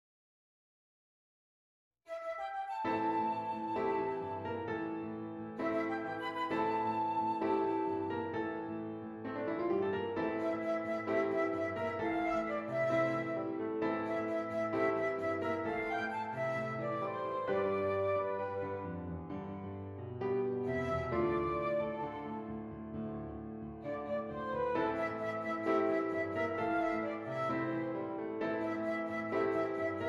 Flute Solo with Piano Accompaniment
A Minor
Moderately